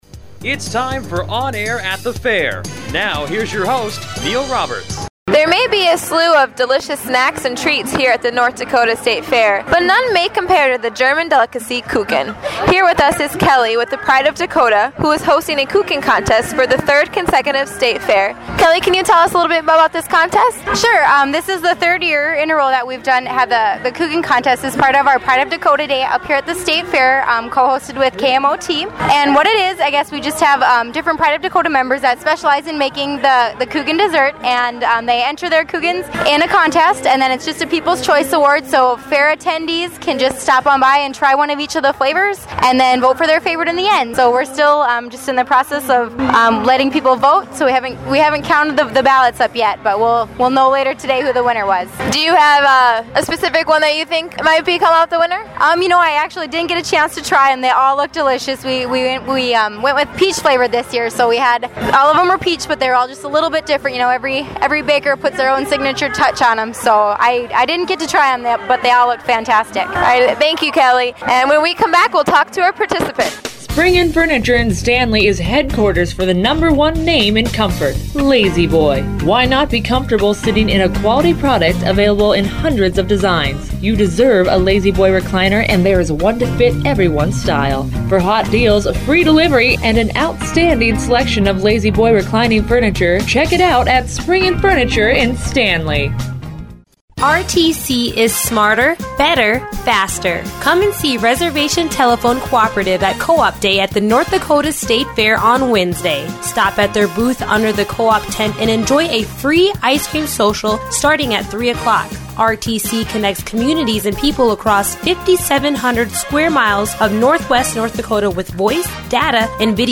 Report 14 - In this report we talk German dessert at the kuchen competition at the NDSF. We talk to both a judge and a competitor about what makes the best kuchen.